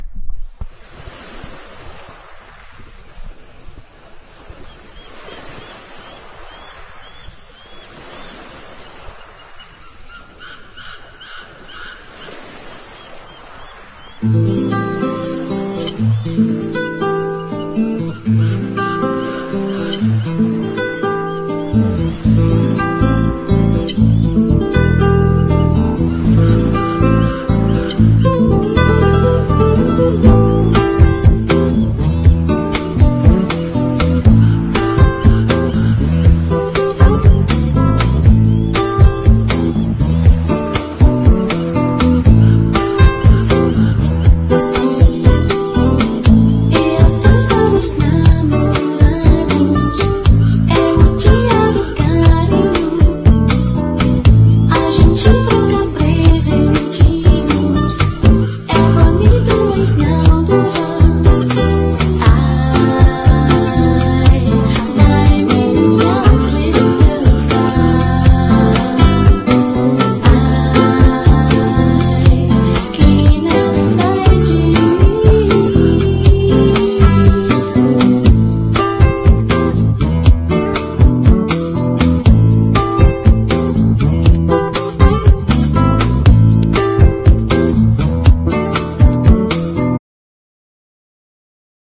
LATIN HOUSE